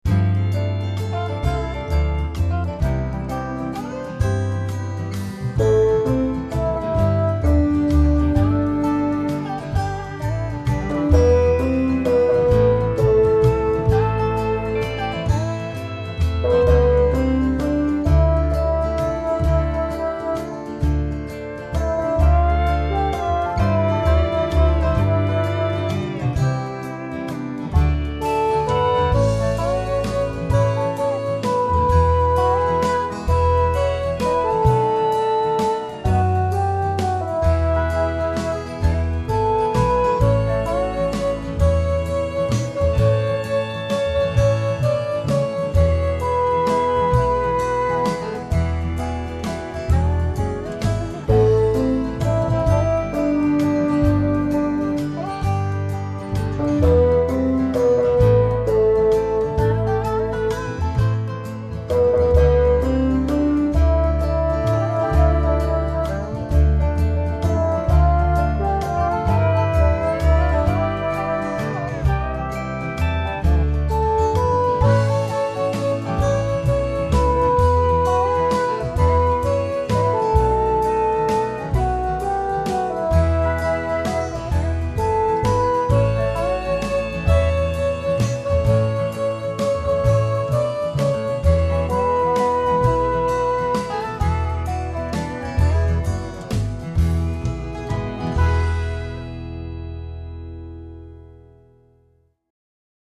The plan is for short, singable songs with allusive texts.
It starts too low and it goes to high, for some reason it changes key for the chorus and it’s a country waltz.